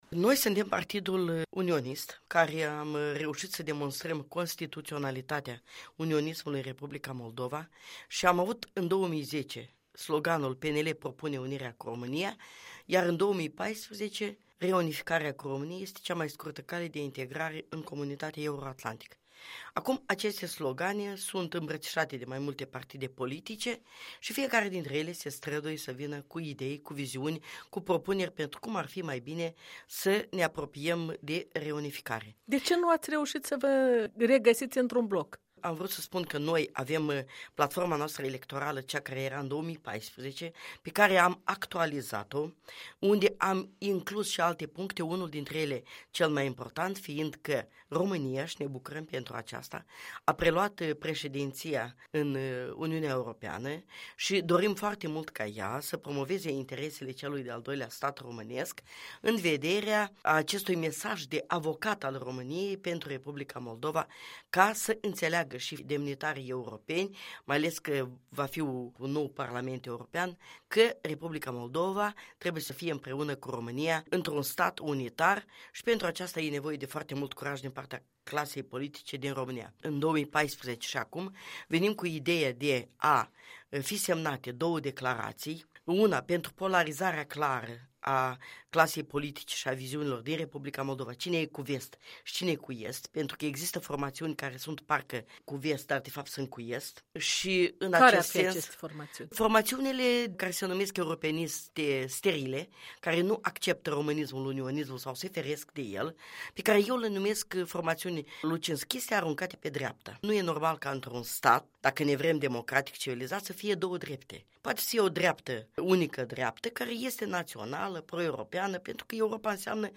Vitalia Pavlicenco și platforma electorală a PNL - in interviu „Eu și votul meu”